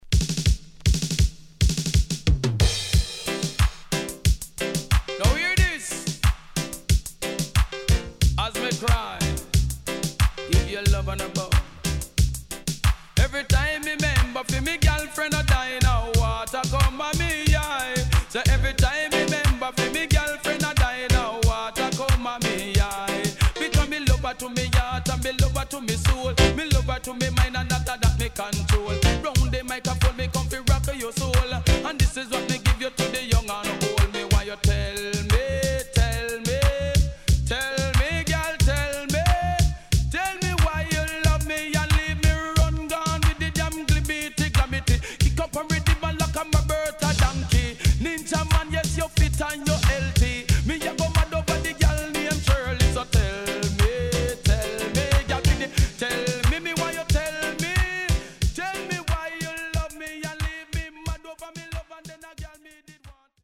HOME > Back Order [DANCEHALL DISCO45]
SIDE A:盤質は良好です。